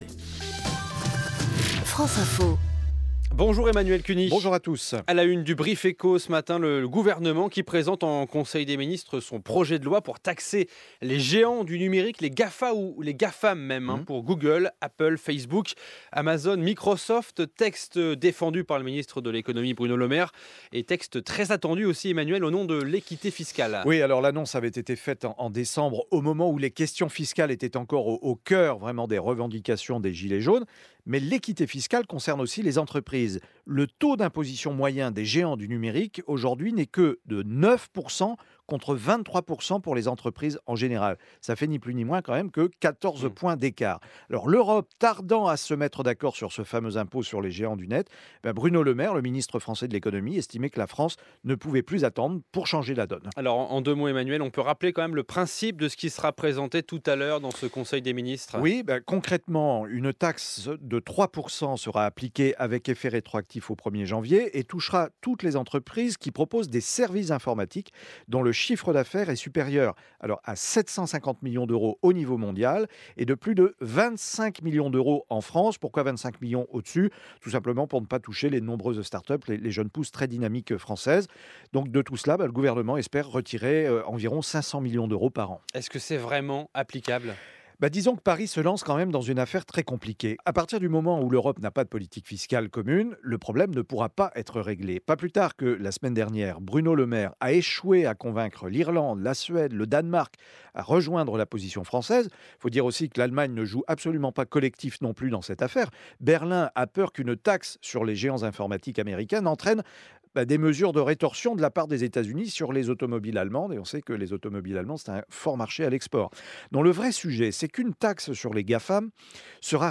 3 mai 2019, France Info.